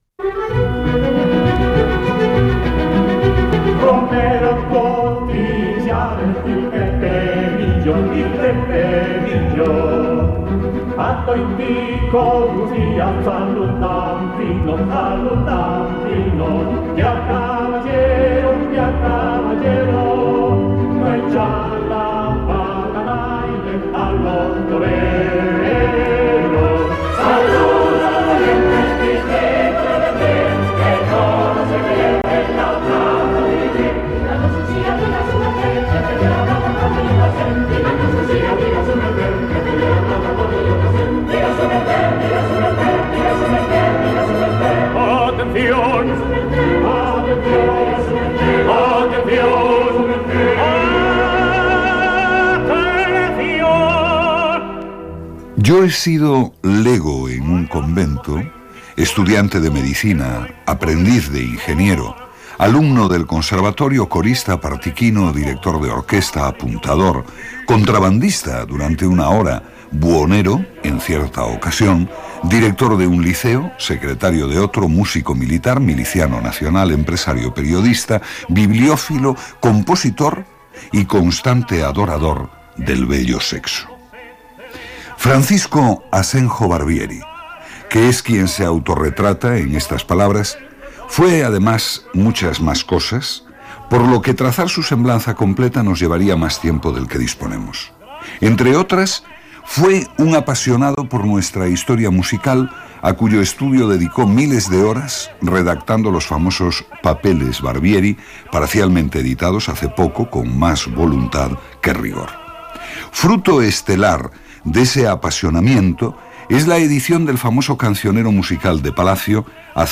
Espai dedicat al compositor Francisco Asenjo Berbieri Gènere radiofònic Musical